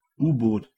Ääntäminen
IPA : /ˈsʌb/